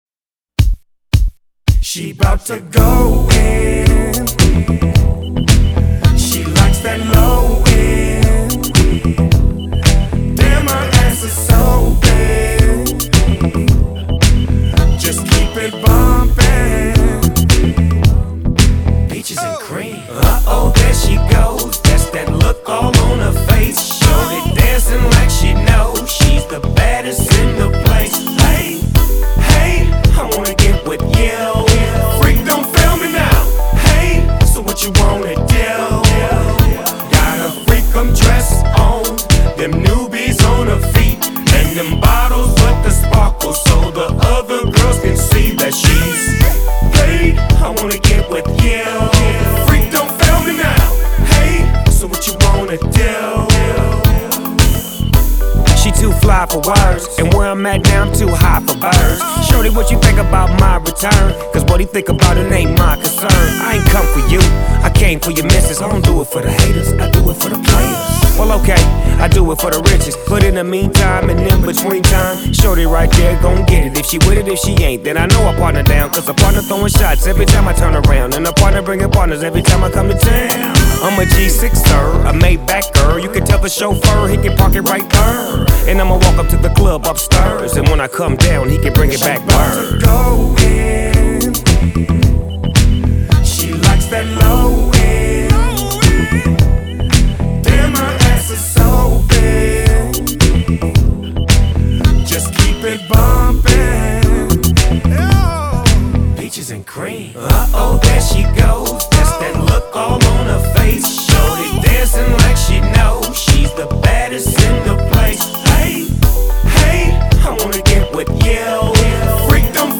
Главная » Файлы » Зарубежный рэп 2016